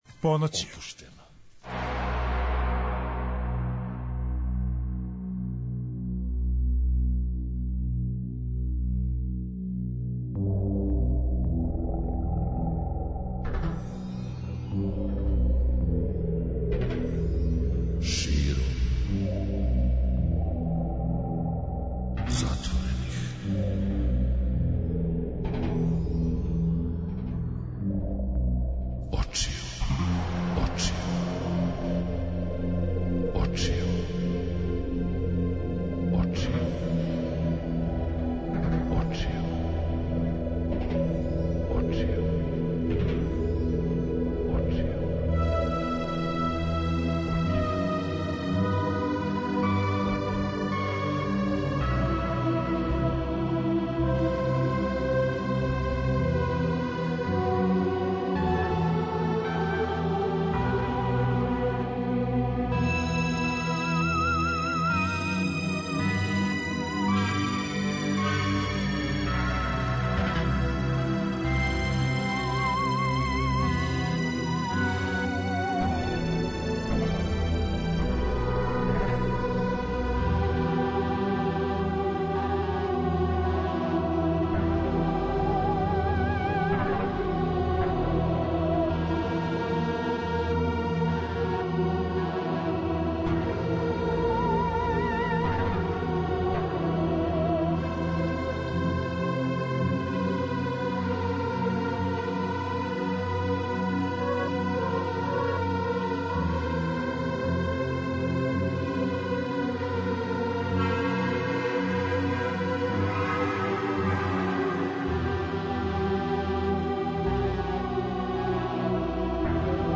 У госте смо позвали пет младих стваралаца чије су области глума, режија, продукција и балет.